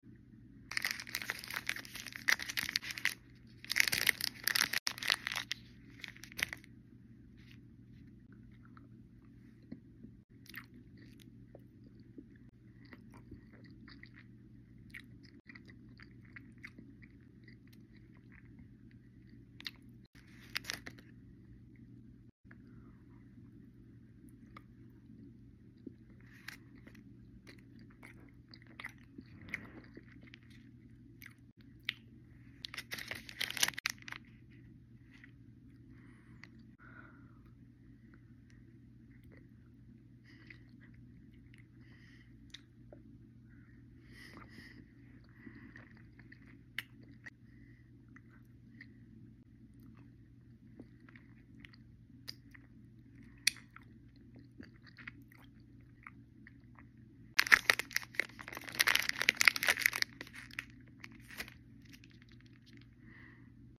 Cadbury Caramello chocolate bar asmr sound effects free download
Cadbury Caramello chocolate bar asmr mukbang 🍫